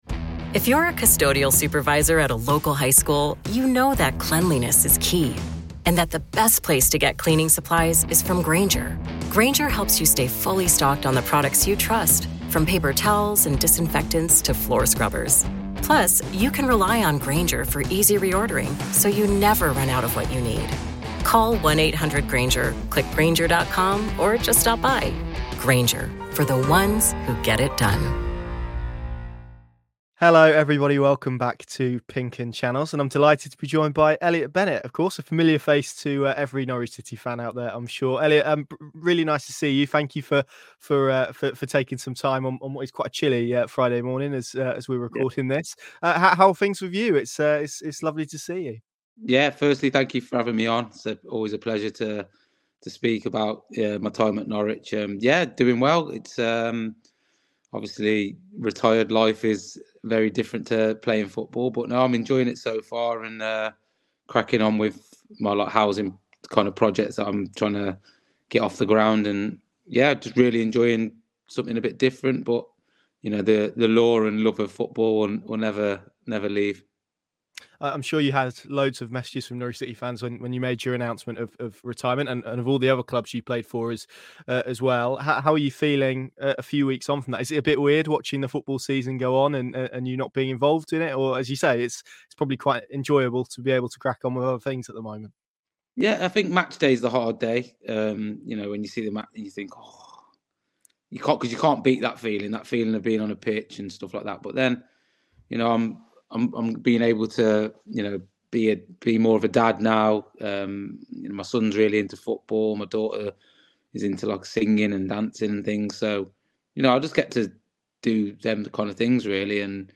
#603 Elliott Bennett Interview | 'I owe a lot to Norwich' | PinkUn Norwich City Podcast